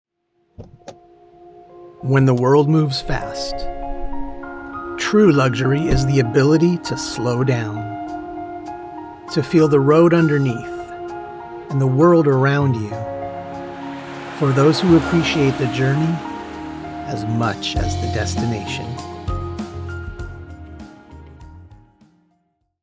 Luxury Car Commercial
My voice quality is clear with a slight amount of "grit" that lends to its uniqueness.